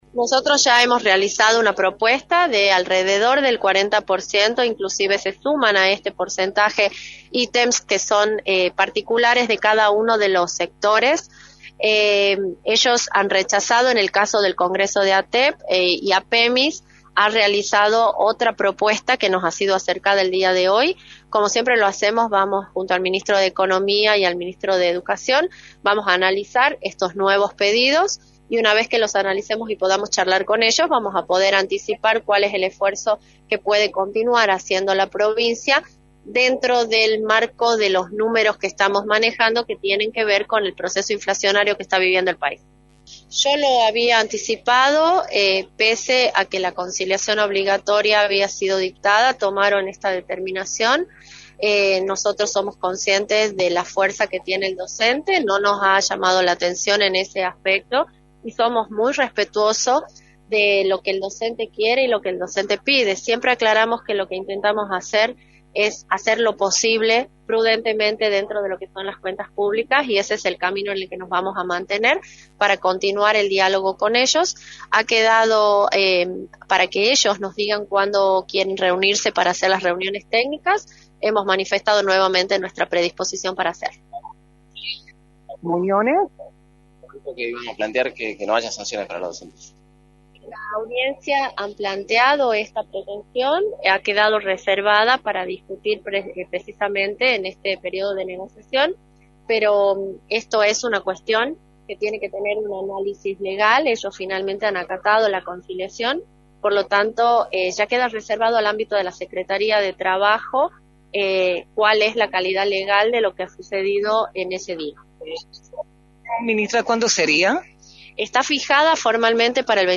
Carolina Vargas Aignasse, Ministra de Gobierno y Justicia, informó en Radio del Plata Tucumán, por la 93.9, los resultados de la reunión que mantuvo con gremios docentes en la Secretaría de Trabajo.